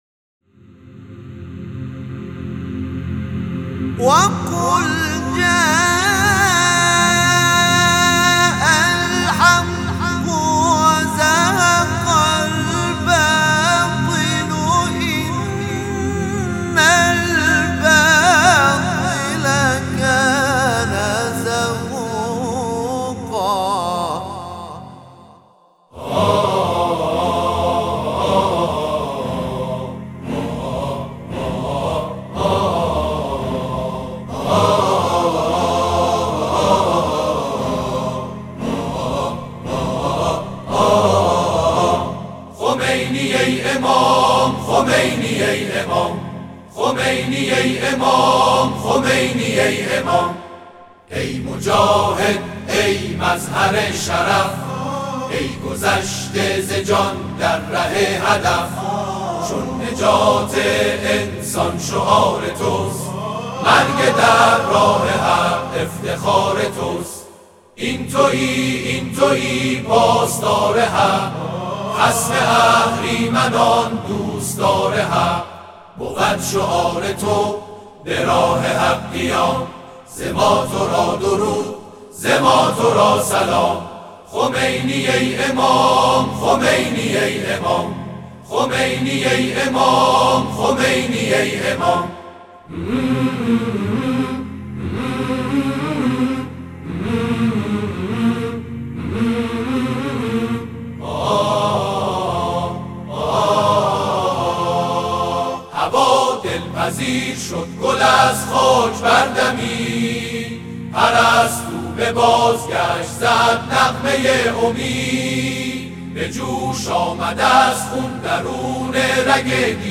اجرای سرودهای انقلابی توسط گروه تواشیح محمد رسول‌الله(ص)
گروه فعالیت‌های قرآنی: گروه تواشیح محمدرسول‌الله(ص) به مناسبت ایام‌الله دهه فجر اقدام به بازخوانی چند سرود انقلابی با تنظیم جدید کرده است.
این اجراها در مراسم ویژه 12 بهمن در مرقد حضرت امام خمینی(ره) که صبح امروز برگزار شد،‌ اجرا شده است.